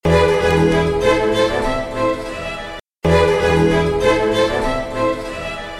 Distortion at low amplitude from vinyl LP recording